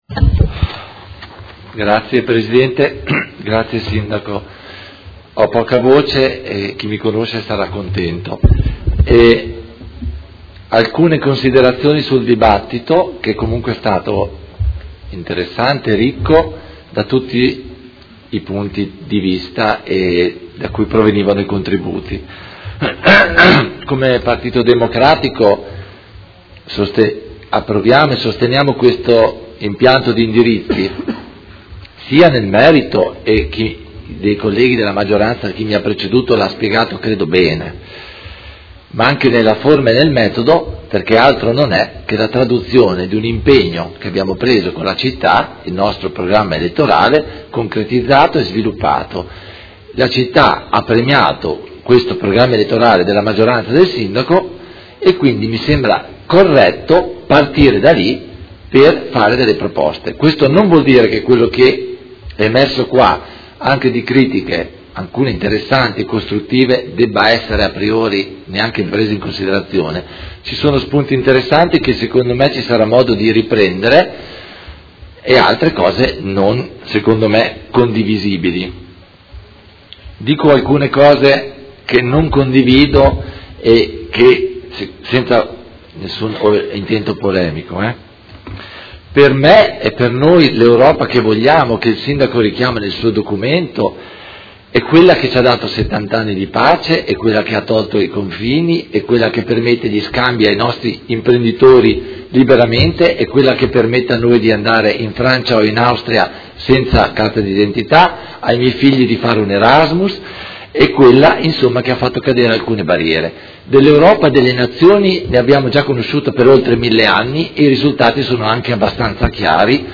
Seduta del 20/06/2019. Dibattito su proposta di deliberazione: Indirizzi Generali di Governo 2019-2024 - Discussione e votazione